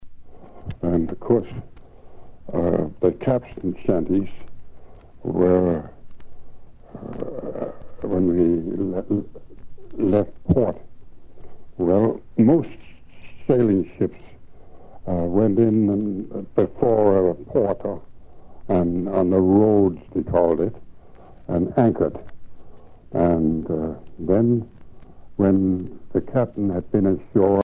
Témoignages et chansons maritimes
Catégorie Témoignage